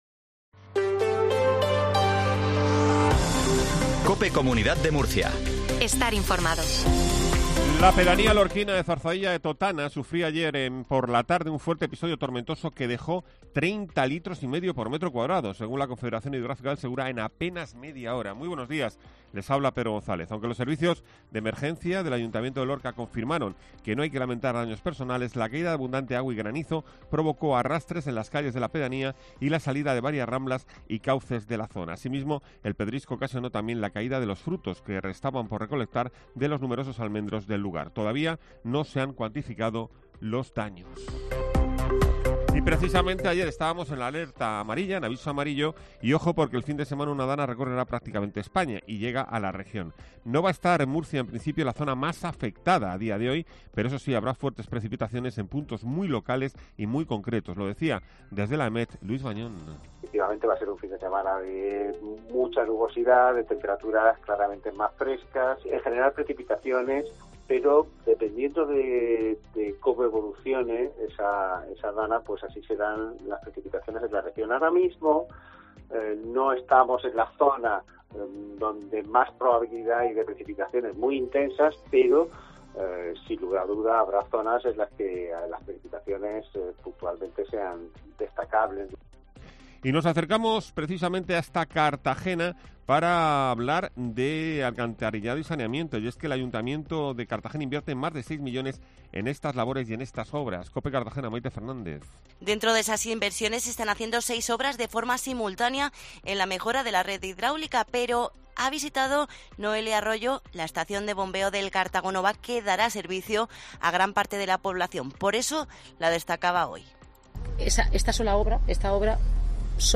INFORMATIVO MATINAL REGION DE MURCIA 0720